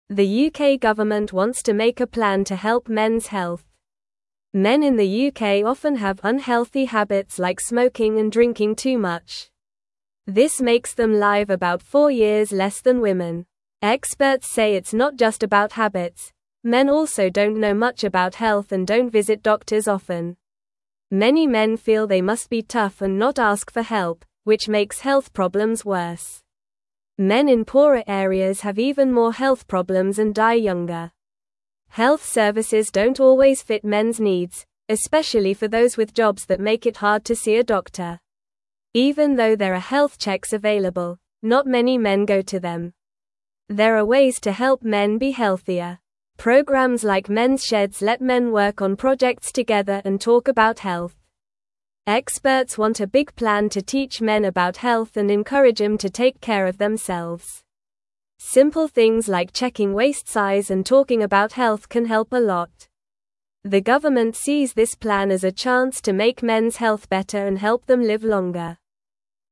English-Newsroom-Lower-Intermediate-NORMAL-Reading-Helping-Men-Be-Healthier-in-the-UK.mp3